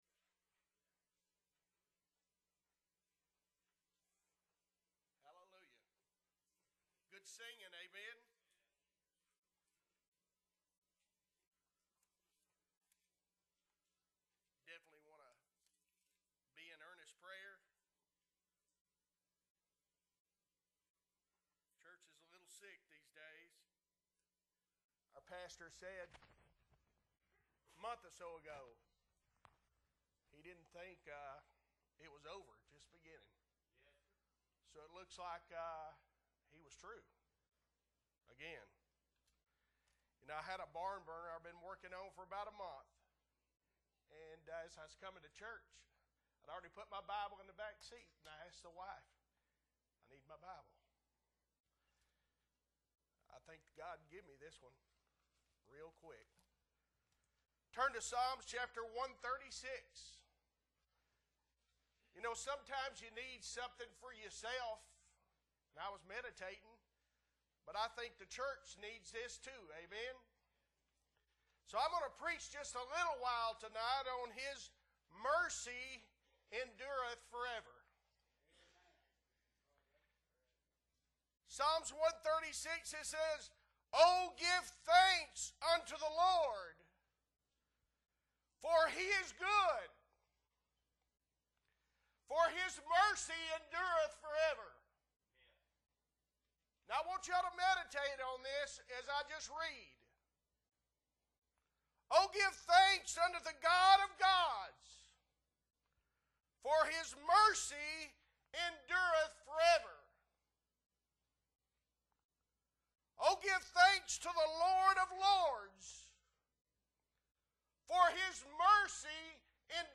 October 15, 2023 Sunday Night Service - Appleby Baptist Church